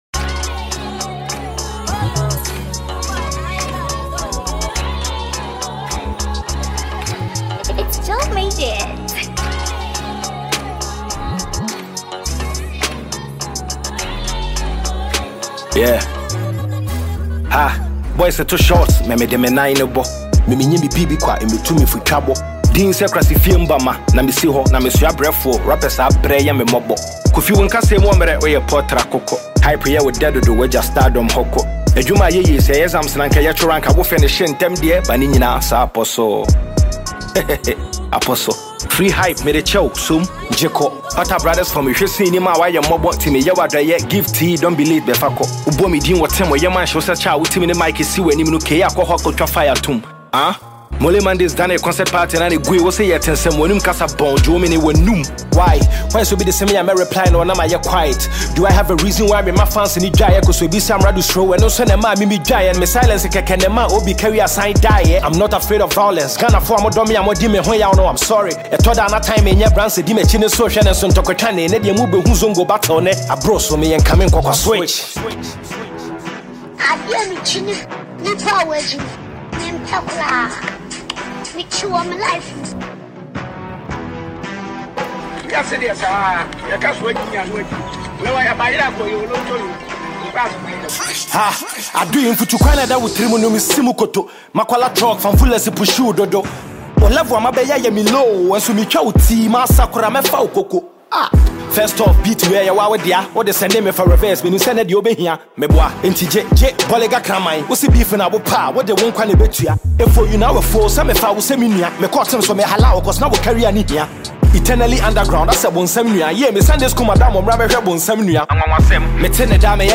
freestyle single